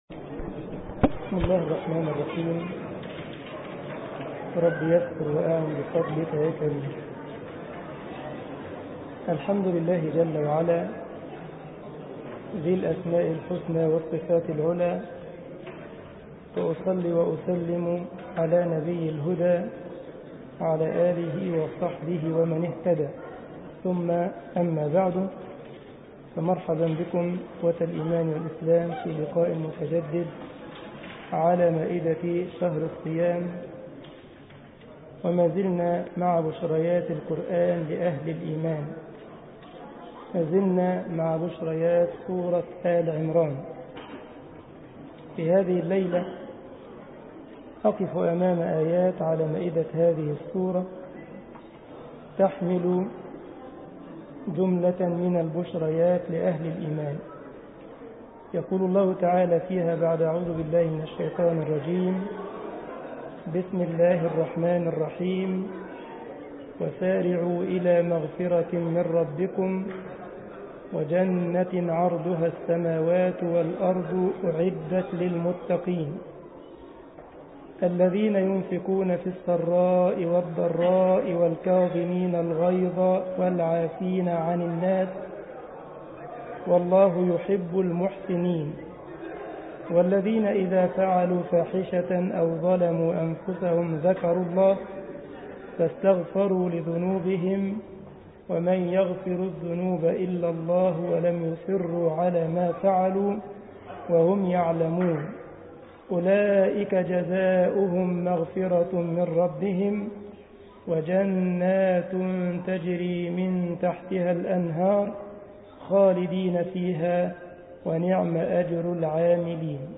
مسجد الجمعية الإسلامية بالسارلند ـ ألمانيا درس 14 رمضان